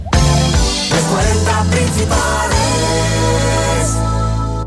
Indicatiu de la cadena
FM